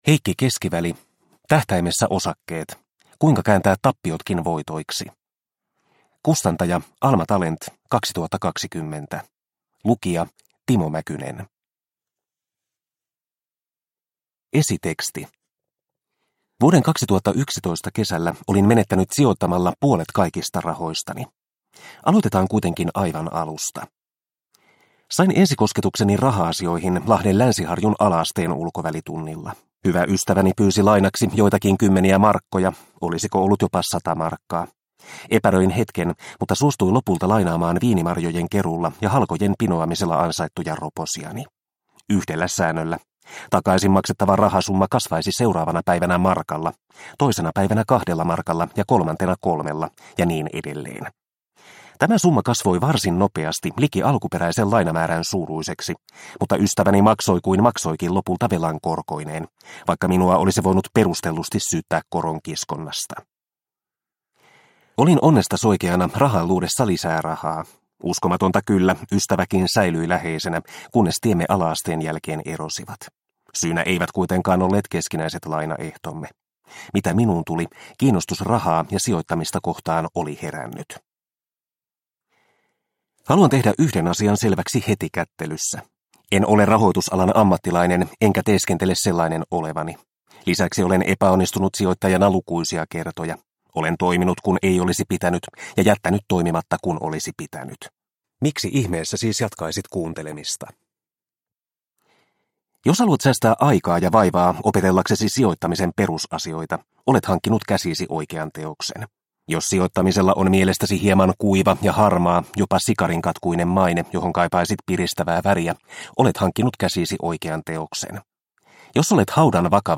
Tähtäimessä osakkeet – Ljudbok – Laddas ner